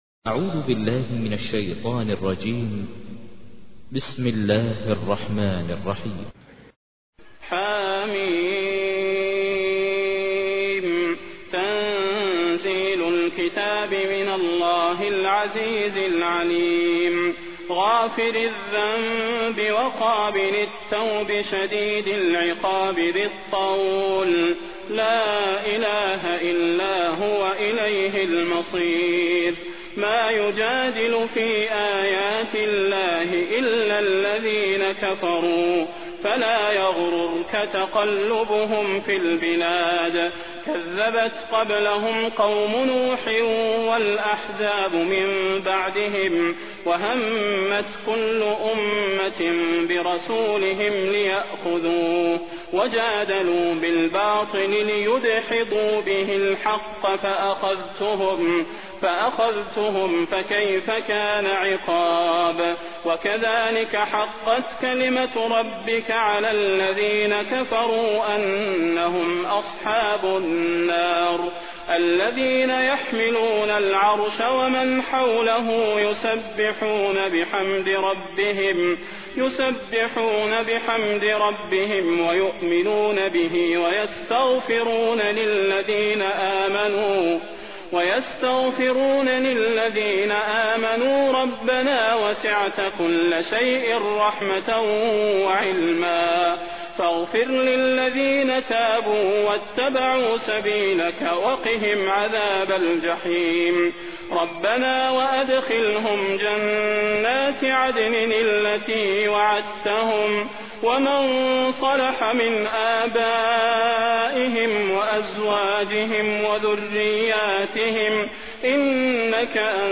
تحميل : 40. سورة غافر / القارئ ماهر المعيقلي / القرآن الكريم / موقع يا حسين